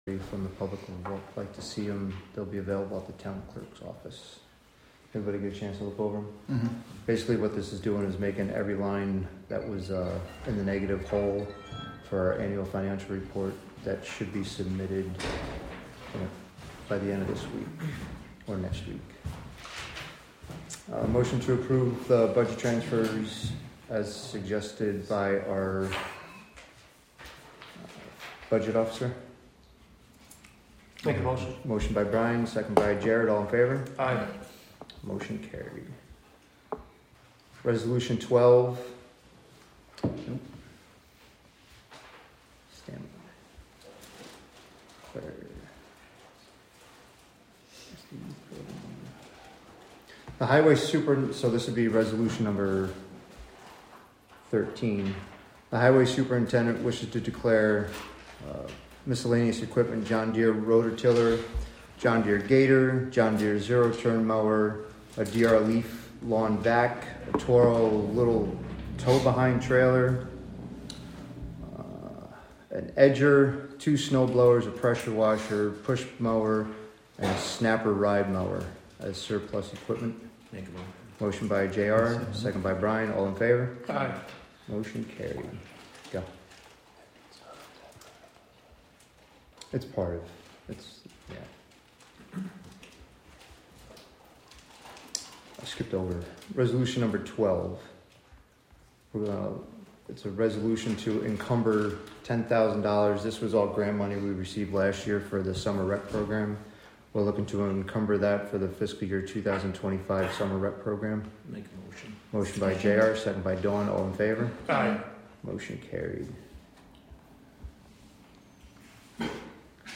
Live from the Town of Catskill: March 4, 2025 Catskill Town Board Meeting (Audio)